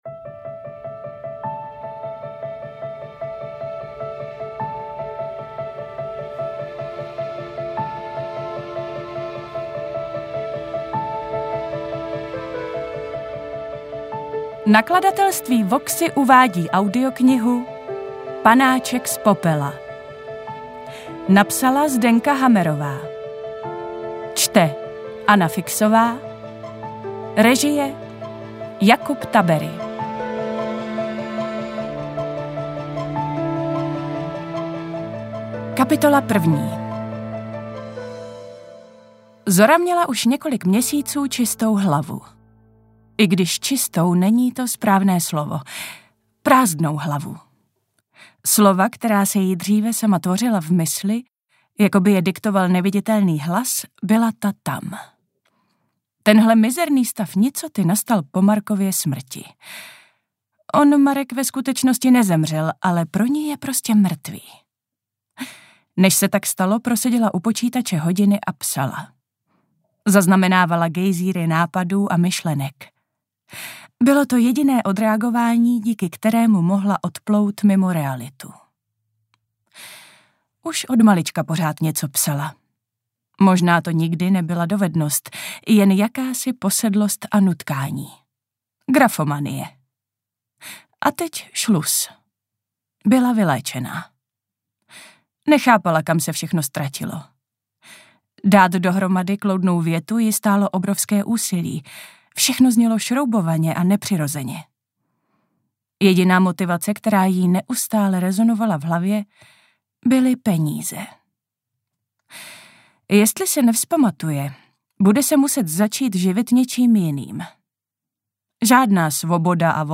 AudioKniha ke stažení, 43 x mp3, délka 9 hod. 50 min., velikost 536,5 MB, česky